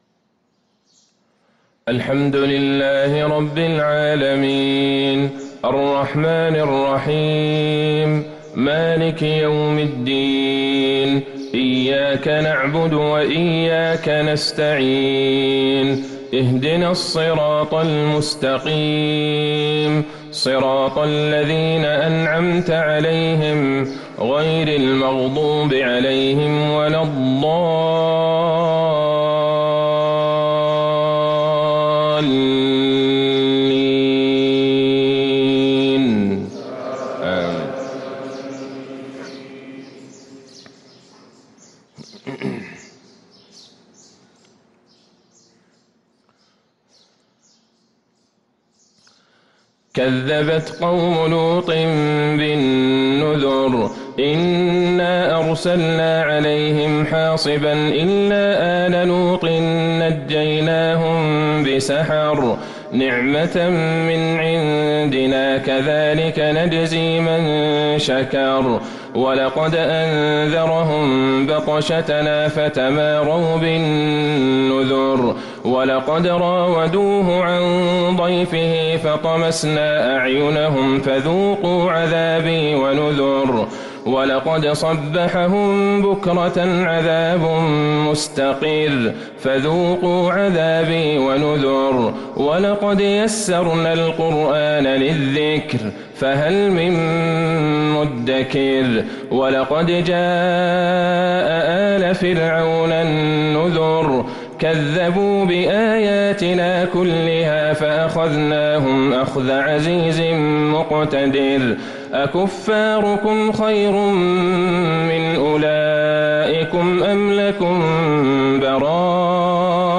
صلاة الفجر للقارئ عبدالله البعيجان 2 رجب 1444 هـ
تِلَاوَات الْحَرَمَيْن .